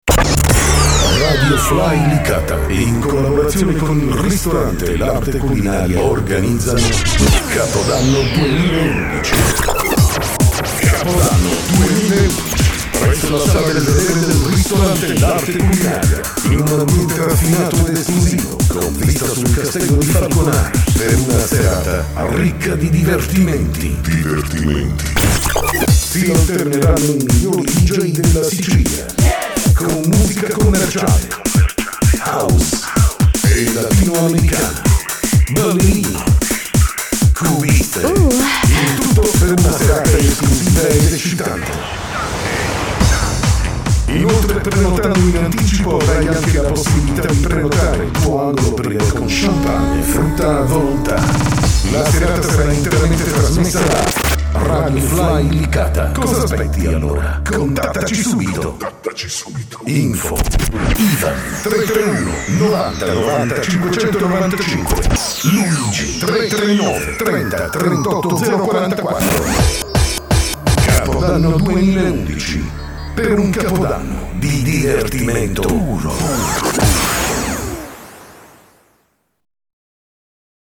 per i ponti tx ecc ho tutto nuovo al max hanno 1 anno... e poi sono tutti siel rvr e aev...in fm è lo stesso....suono da paura.